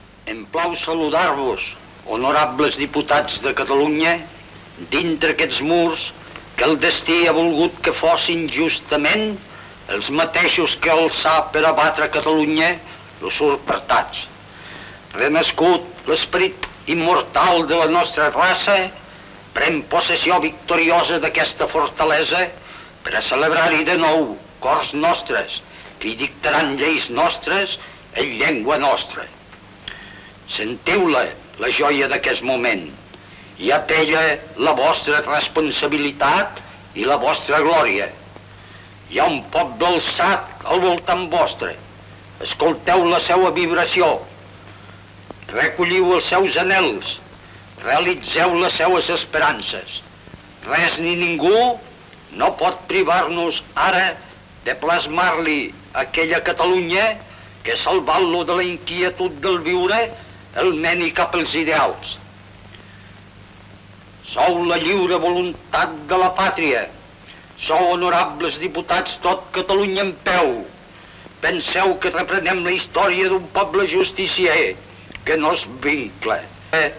Discurs del president interí de la Generalitat de Catalunya Francesc Macià el dia de la inauguració del Parlament de Catalunya.
Informatiu